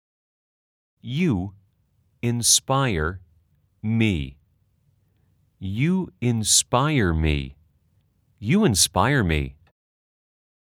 아주 천천히-천천히-빠르게 3번 반복됩니다.
/ 유 인스빠이어 미 /
inspire는 /인스파이어/ 말고 /인스빠이어/라고 해주세요.